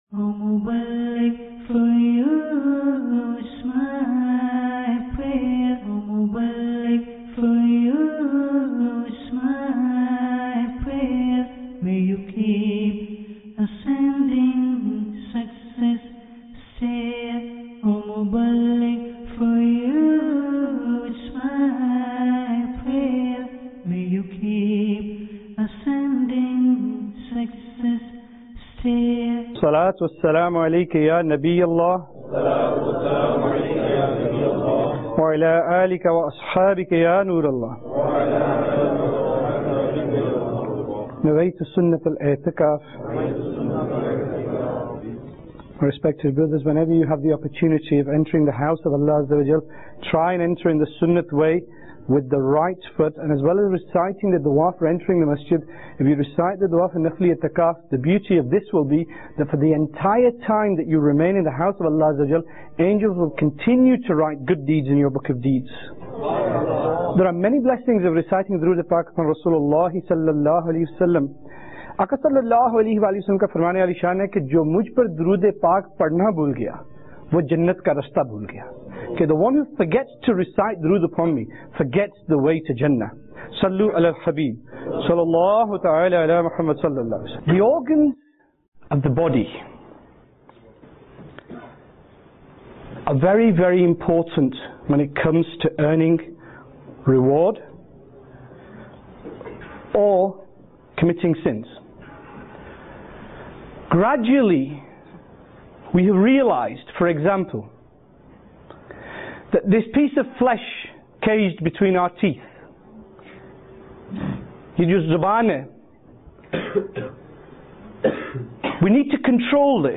Sunnah Inspired Bayan Ep 211 - Destruction Of The Eyes Oct 19, 2016 MP3 MP4 MP3 Share The description of the most important organ of our body, the best gift Almighty Allahعَزَّوَجَلَّ gave the human body. That gift is our eye, which we have to protect from evil because the ultimate result for a bad deed or (sin) is initiated commencing from the eye.